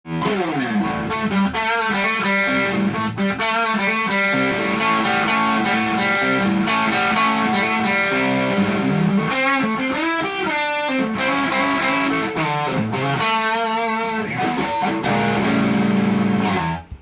ネックがかなり太く、音もギブソンと同じような太さがしました。
歪ませてもかなりの太めのサウンドです。